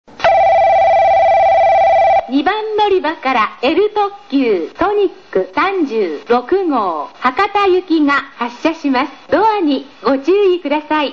発車放送（L特急ソニック・博多）